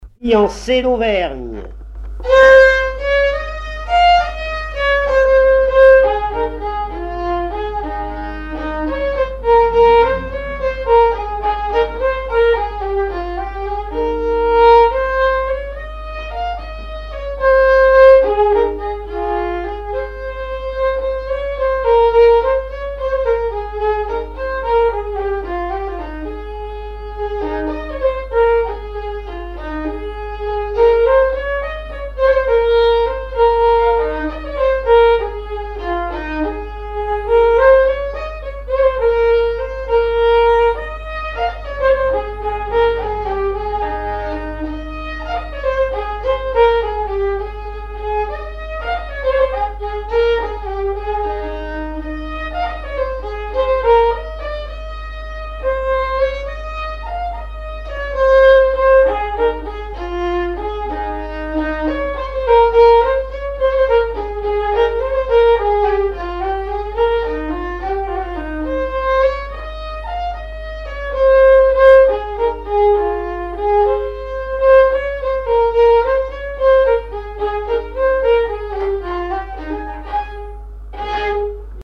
danse : valse
recherche de répertoire de violon pour le groupe folklorique
Pièce musicale inédite